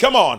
VOX SHORTS-1 0006.wav